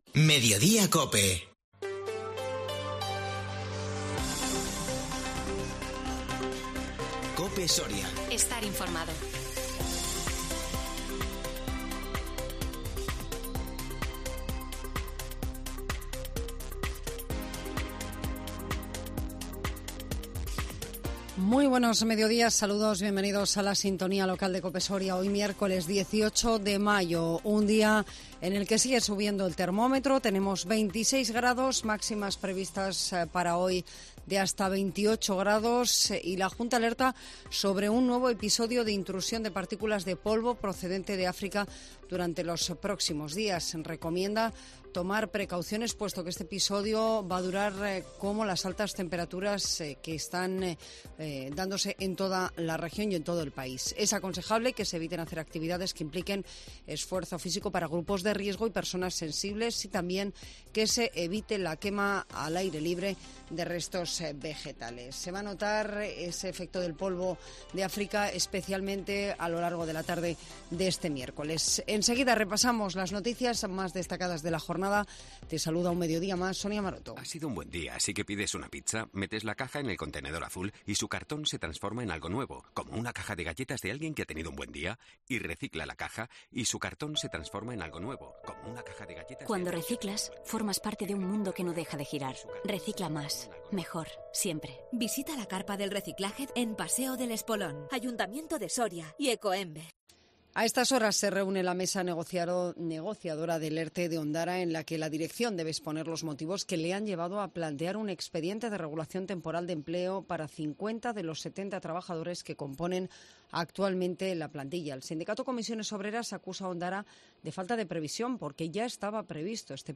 INFORMATIVO MEDIODÍA COPE SORIA 18 MAYO 2022